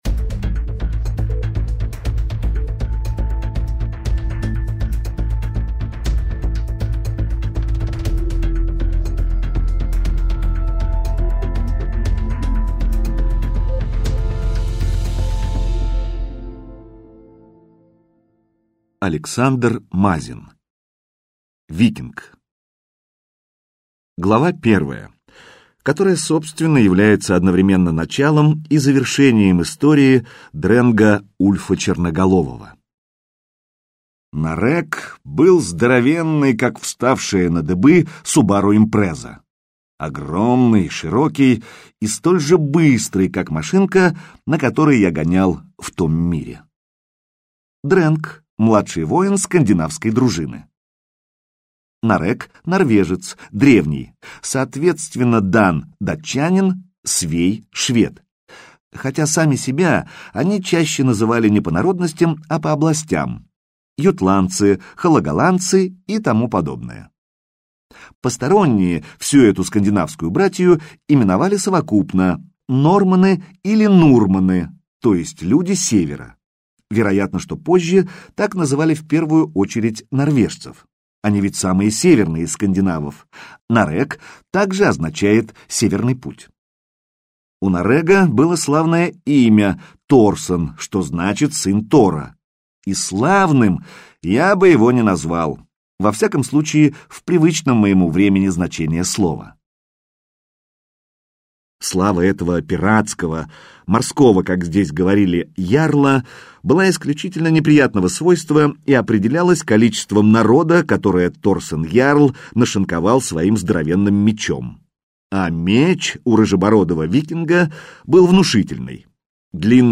Аудиокнига Викинг - купить, скачать и слушать онлайн | КнигоПоиск